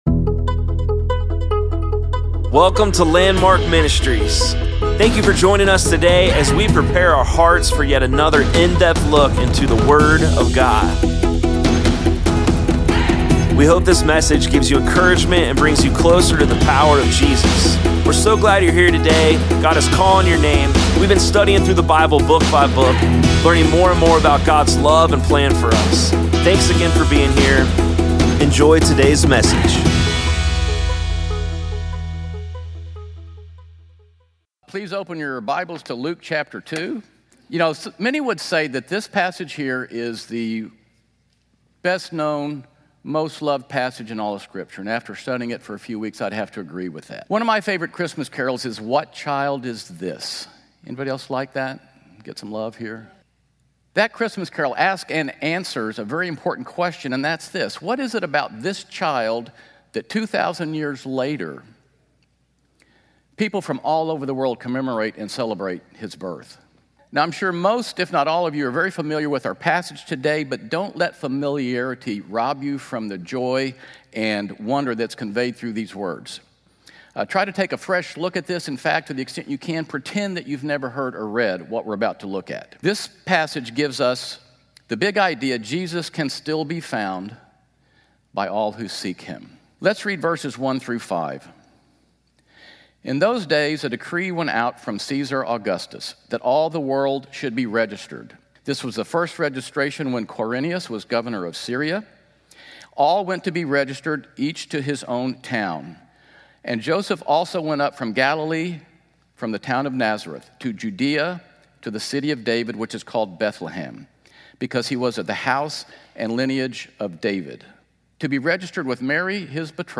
Sermons | Landmark Church of Clermont County, Ohio